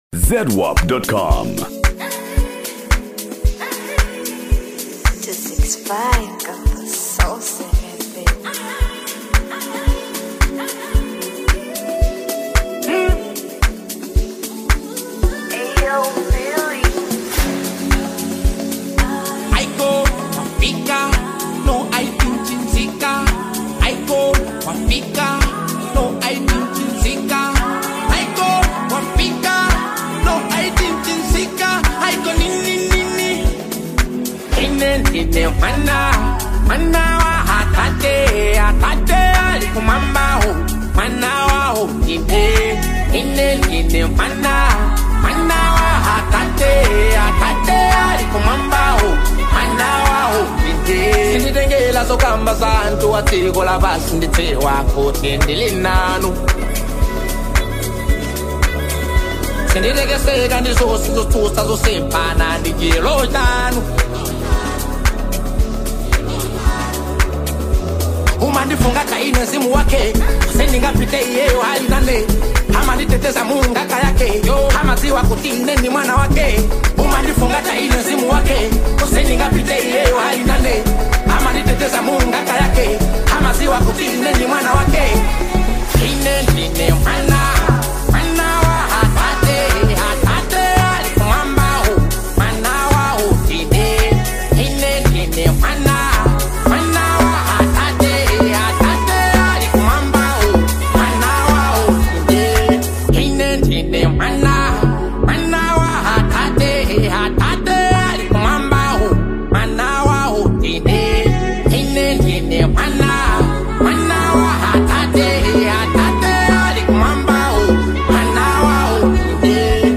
Genre: Afro-Beats
blending catchy melodies with meaningful songwriting.